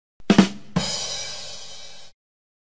Joke Sound Effect (Ba dum tss!)